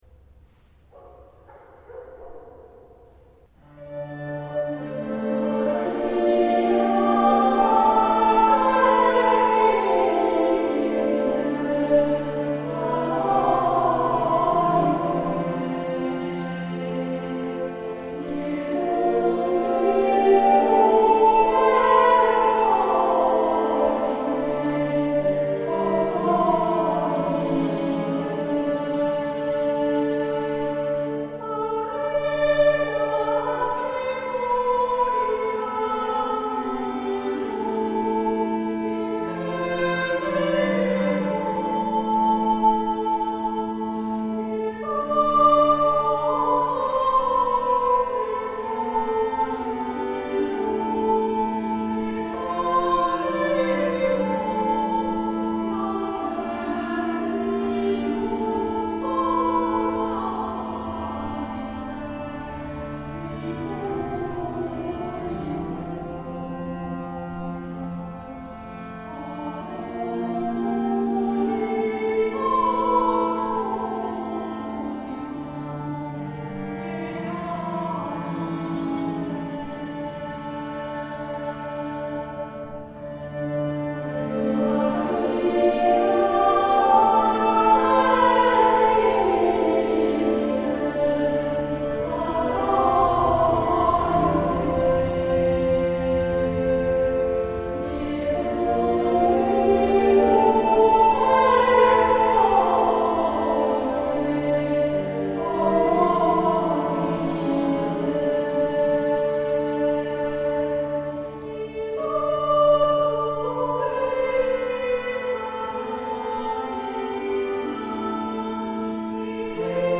polyfone
met hondengeblaf als introductie